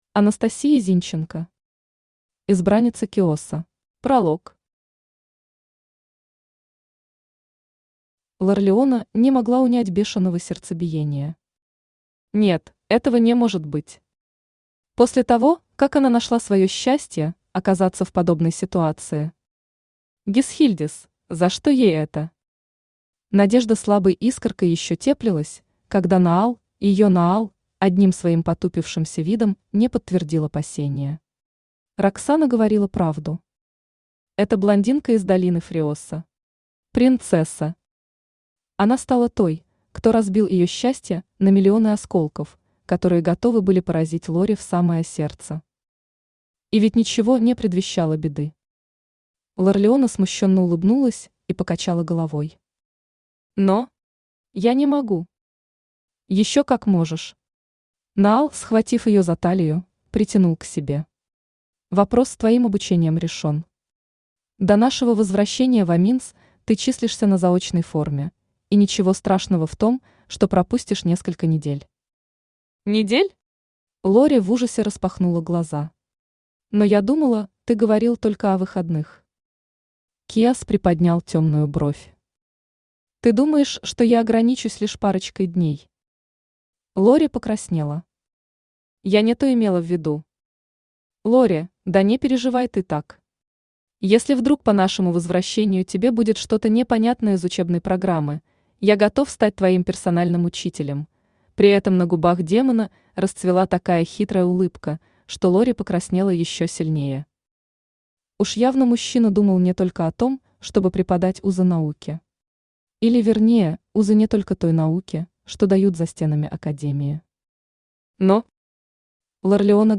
Аудиокнига Избранница киоса | Библиотека аудиокниг
Aудиокнига Избранница киоса Автор Анастасия Зинченко Читает аудиокнигу Авточтец ЛитРес.